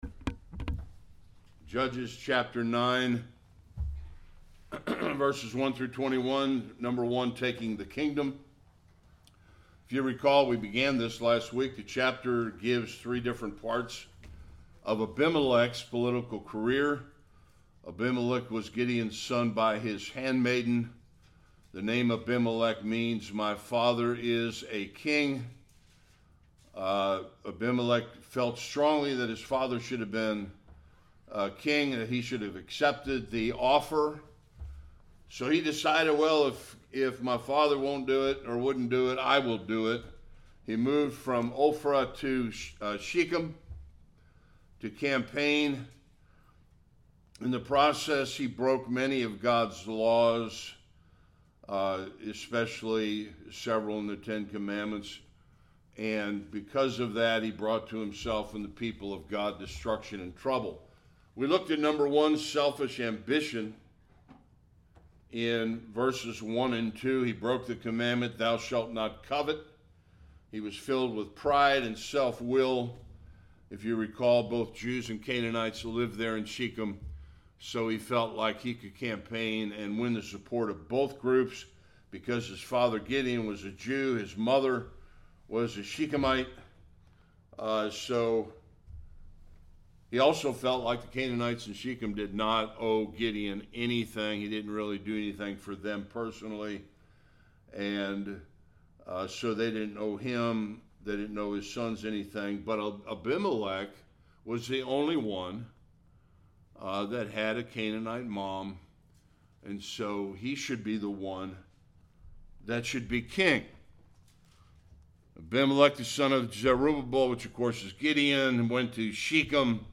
1-21 Service Type: Sunday School After Gideon’s death